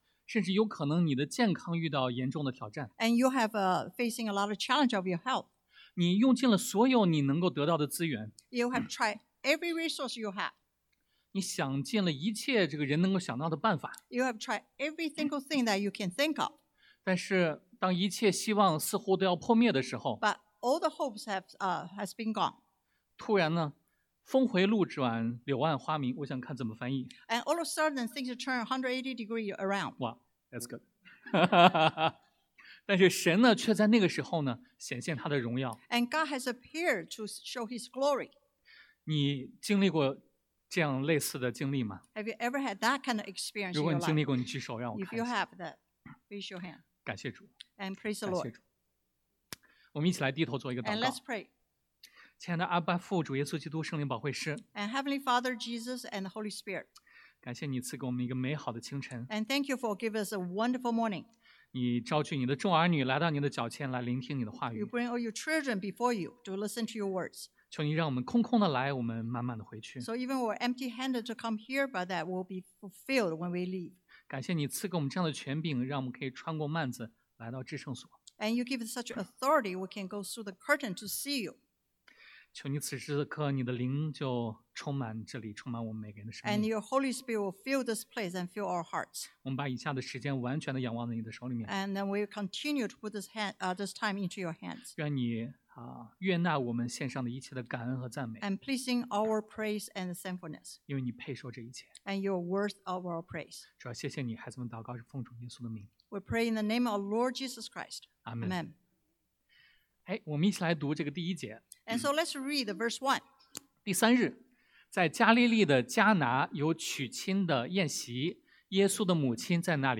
Passage: 约翰福音 John 2:1-11 Service Type: Sunday AM God Cares About Our Needs 神关心我们的需要 God’s Timing is Always Perfect 神的时间总是完美 God’s Supply Exceeds Our Requests 神的供应超过所求